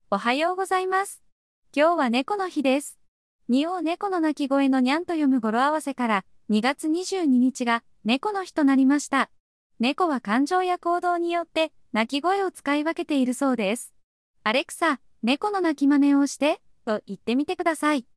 With emotions, you can have Alexa respond in a happy/excited tone when a customer answers a trivia question correctly or wins a game.
Japanese - Excited       Audio Sample
Excited_medium_Good_morning.